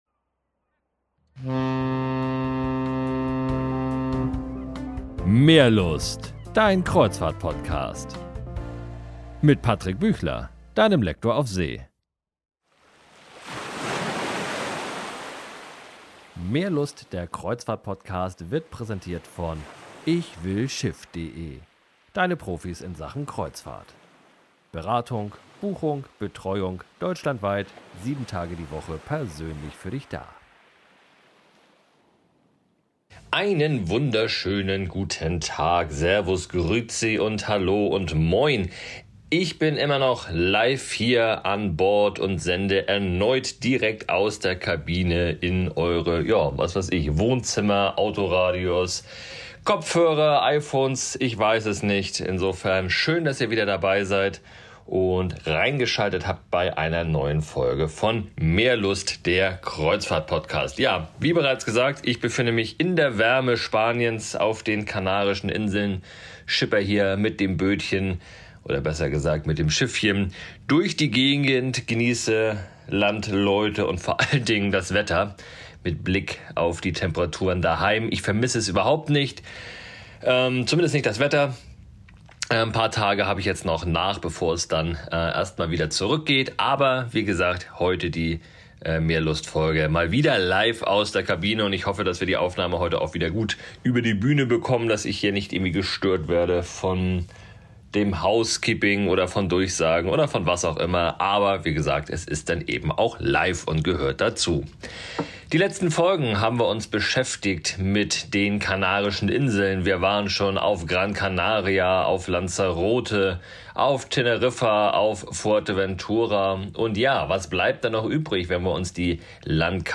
herzlich Willkommen live aus seiner Kabine.